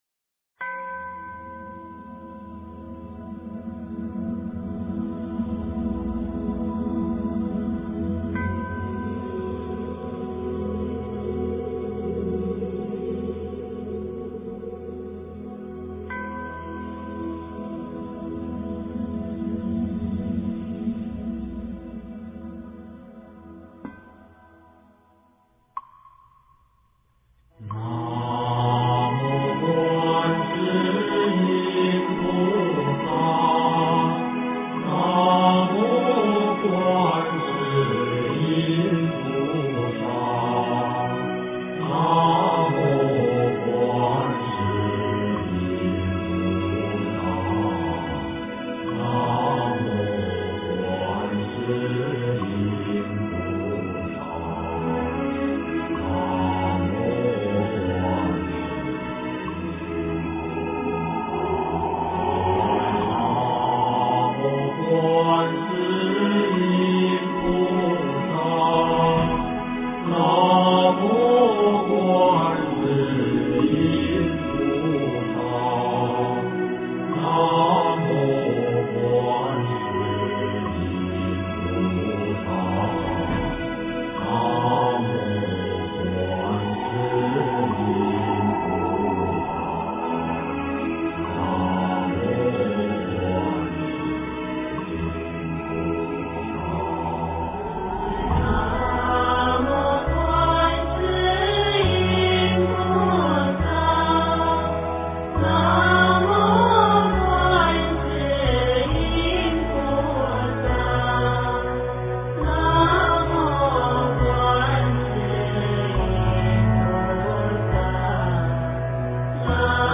观音圣号五音调--佛光山 经忏 观音圣号五音调--佛光山 点我： 标签: 佛音 经忏 佛教音乐 返回列表 上一篇： 四生九有-功课--佛光山梵呗团 下一篇： 晚课二--普寿寺尼众 相关文章 南无阿弥陀佛--男女6音调 南无阿弥陀佛--男女6音调...